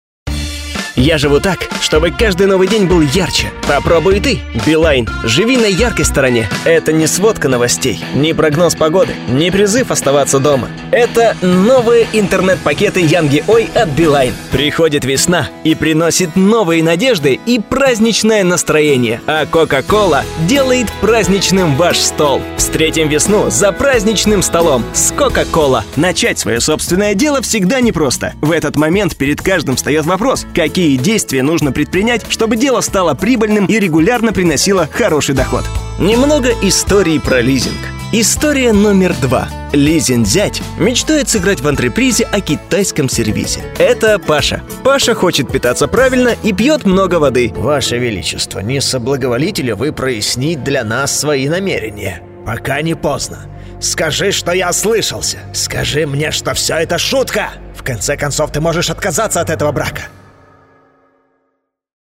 СУПЕР ПОЗИТИВНЫЙ ДИКТОР! Молодёжный, энергичный, игровой, мультяшный, сказочный, серьёзный, страшно серьёзный и не только.
Тракт: Rode NT1 Black Single, Roland RMC-G50, DBX 376, Digilab Ginger SPM-100, Audient iD14, Roland UA-55 Quad-Capture, акустическая кабина, голосовые связки.
Демо-запись №1 Скачать